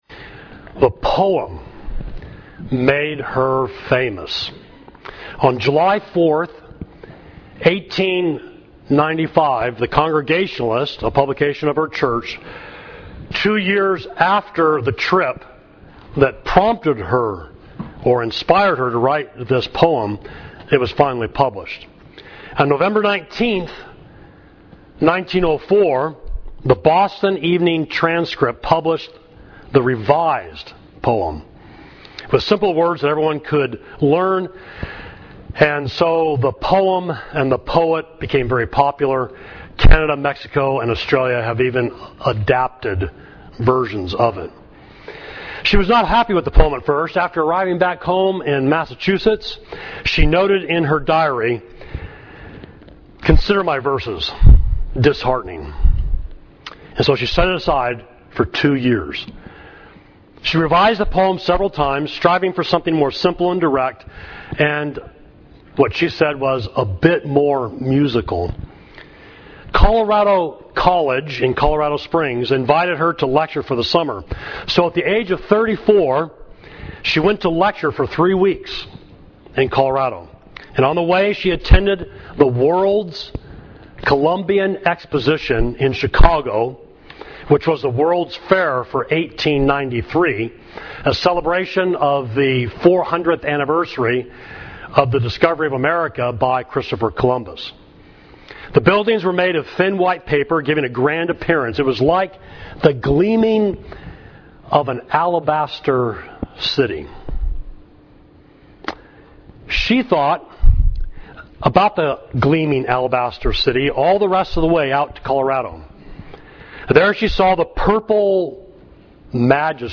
Sermon: A the B